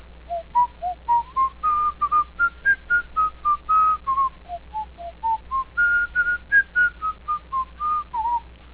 cheery but banal theme music (a whistled .WAV version).